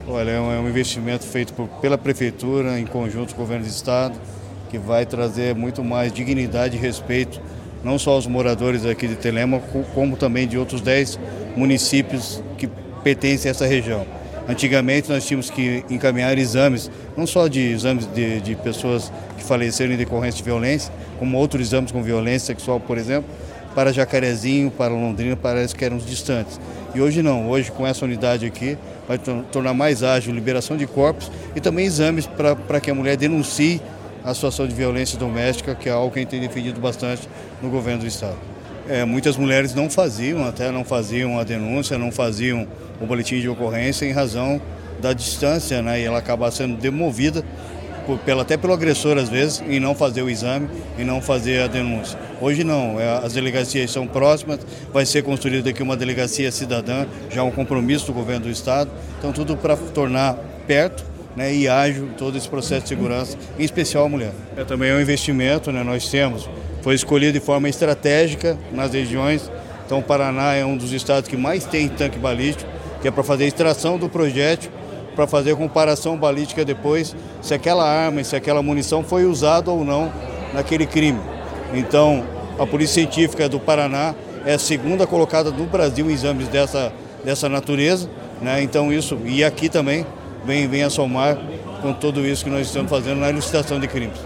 Sonora do secretário da Segurança Pública, Hudson Leôncio Teixeira, sobre a nova sede da Polícia Científica de Telêmaco Borba